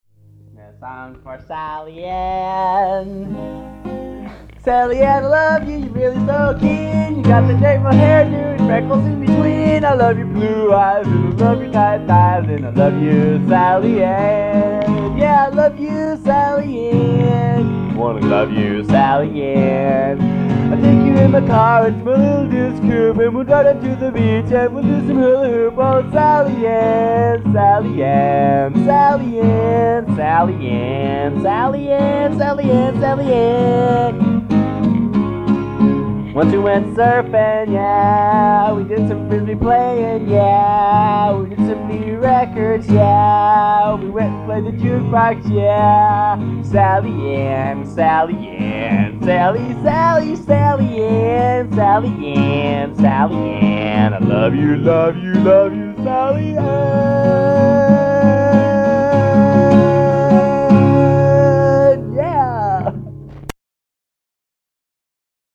I think this was an improvisation.
guitar